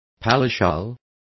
Complete with pronunciation of the translation of palatial.